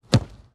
sfx-kick.mp3